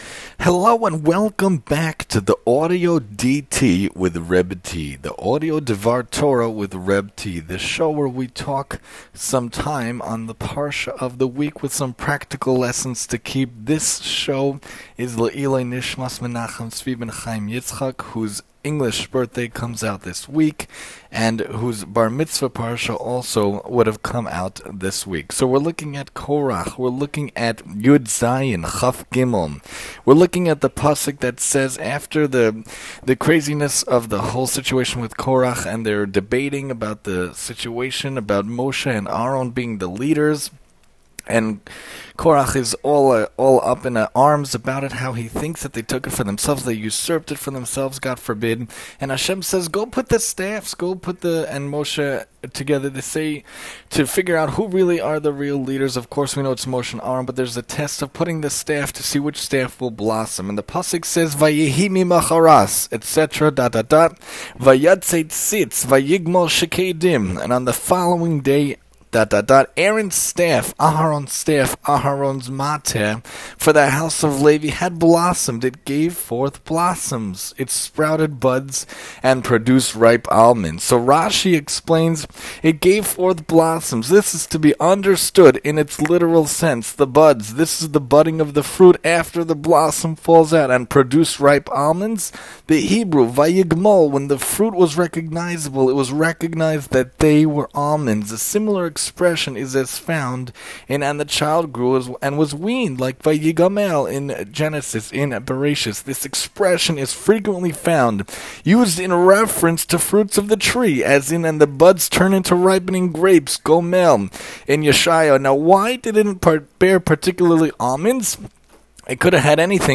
The Audio DT, Korach Mini Lecture: Miracles Abound